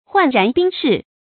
涣然冰释 huàn rán bīng shì
涣然冰释发音
成语正音 涣，不能读作“huān”。